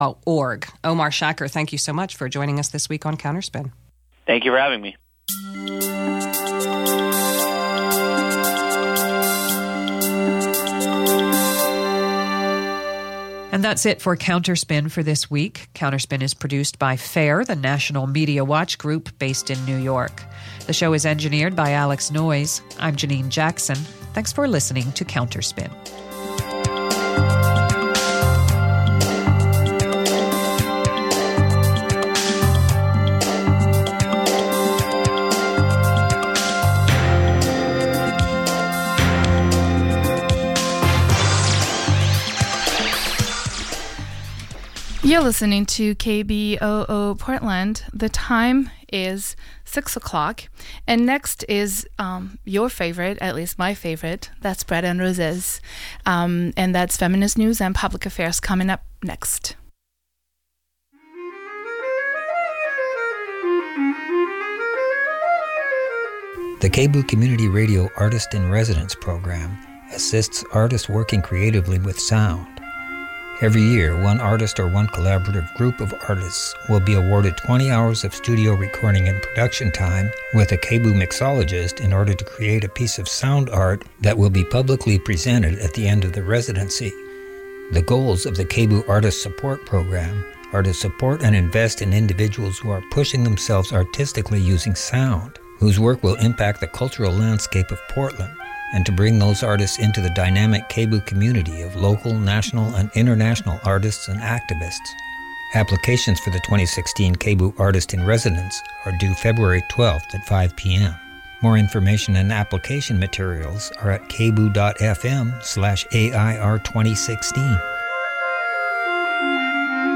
Young Artists Live Performances